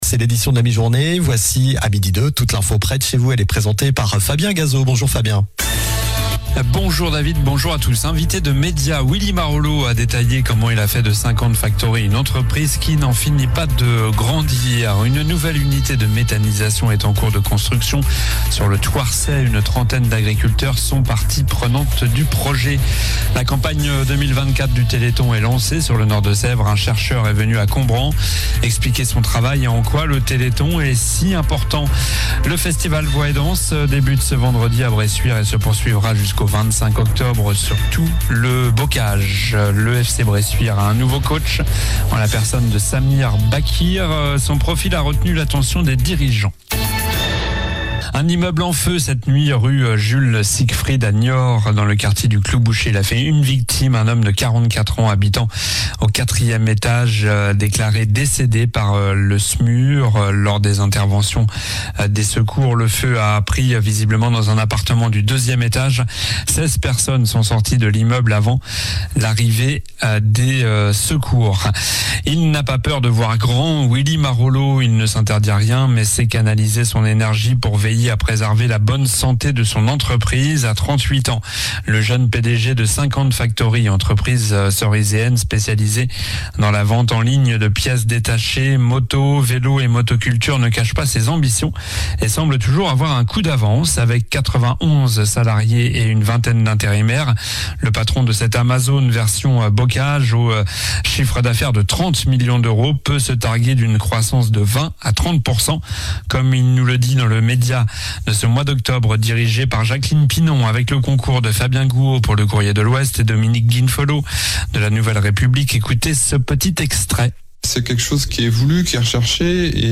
Journal du mercredi 02 octobre (midi)